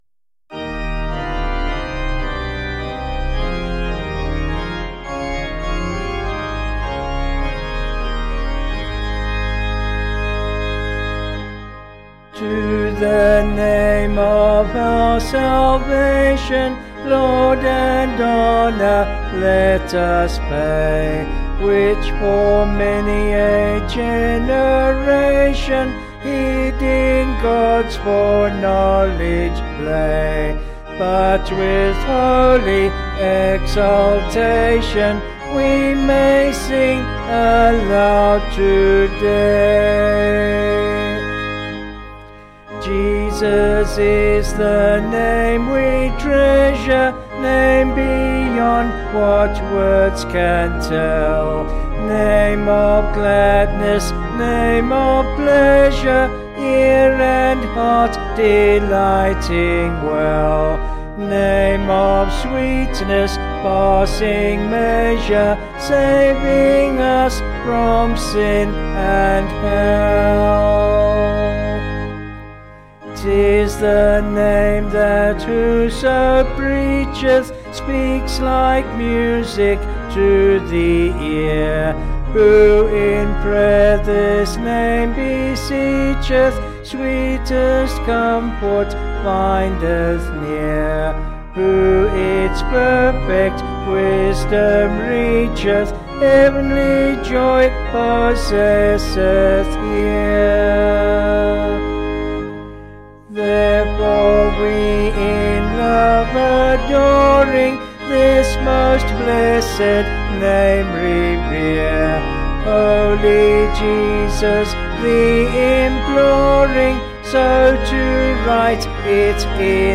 Vocals and Organ   264.3kb Sung Lyrics